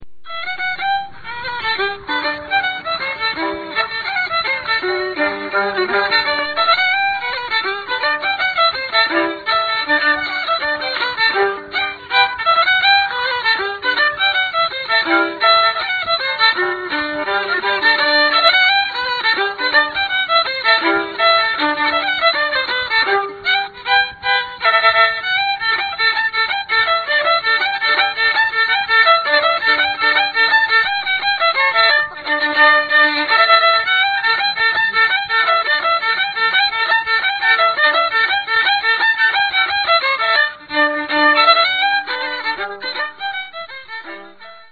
Music of English and Welsh travellers and gypsies
I can think of almost no other English Traveller fiddler to have appeared on a commercially available recording - and he was a good one too (sound clip - The Breakdown).  He was recorded in Kent, purely by chance, when he was there doing a bit of seasonal work in 1962.